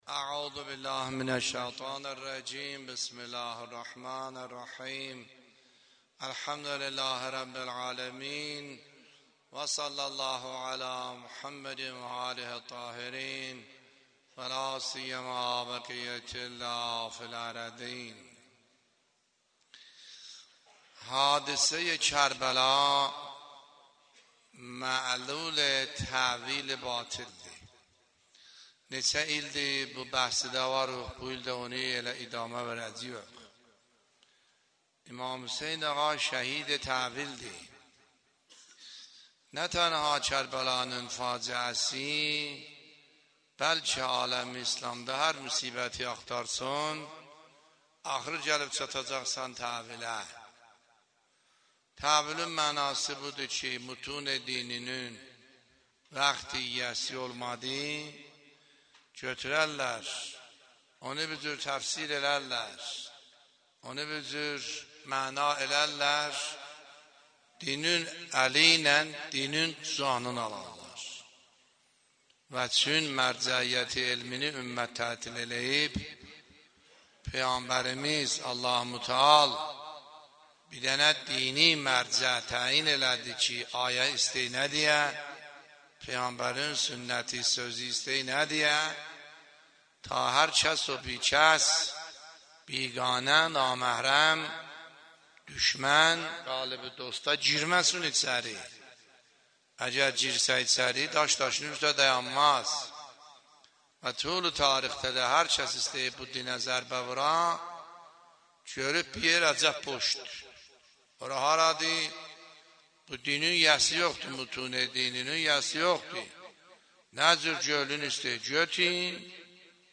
سخنرانی آیه الله سیدحسن عاملی فایل شماره ۱ - دهه اول محرم ۱۳۹۷